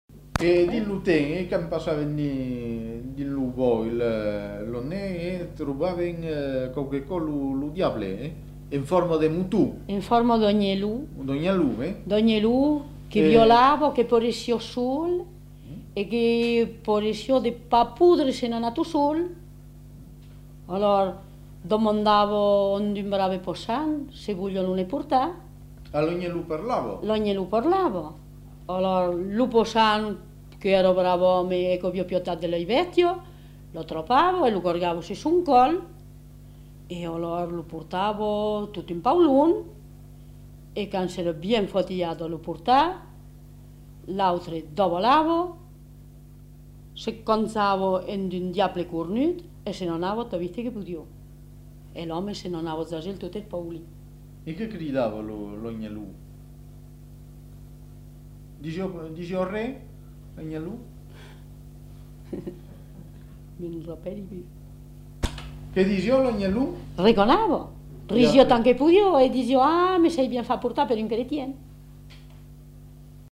Aire culturelle : Périgord
Lieu : Castels
Genre : conte-légende-récit
Type de voix : voix de femme
Production du son : parlé
Classification : récit de peur